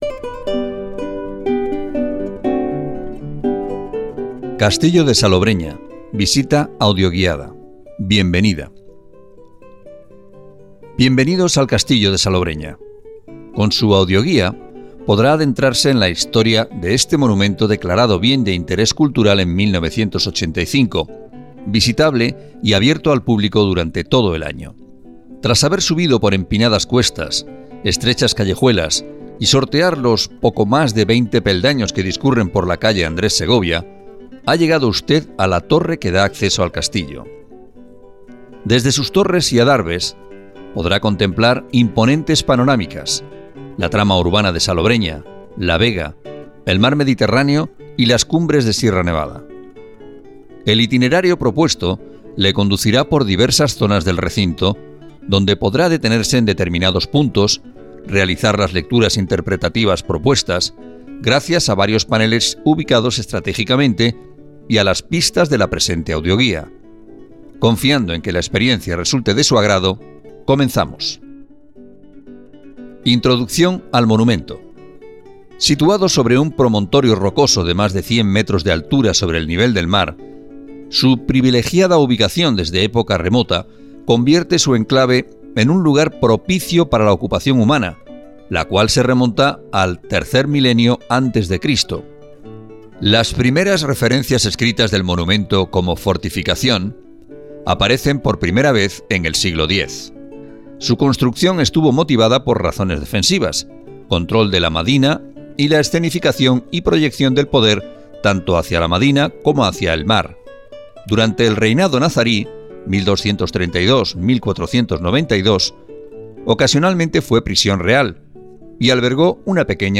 Audioguía oficial del castillo de Salobreña